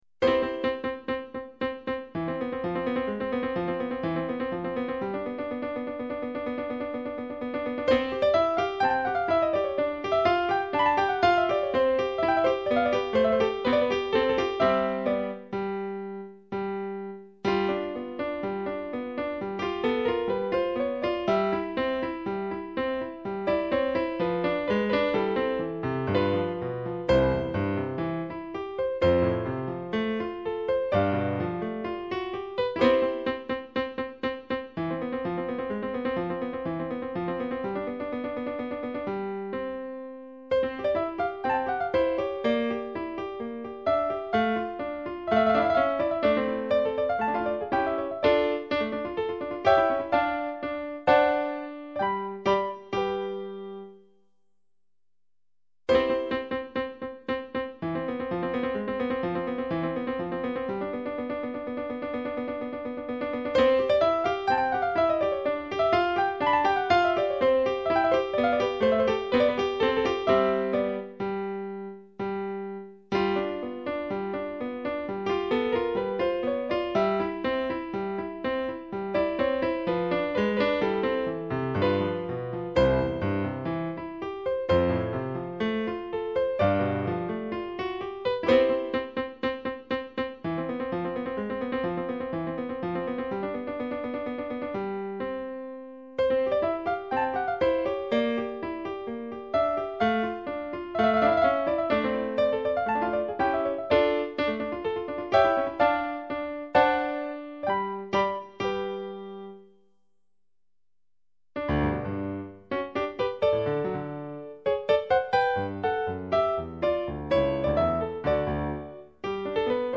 Klassik